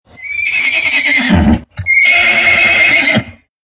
Hör hin! Was ist das? Meine Bauernhoftiere Mit Soundmodulen mit echten Tierstimmen Hans-Günther Döring (Autor) Buch | Kinder-Pappbuch 12 Seiten 2019 | 3.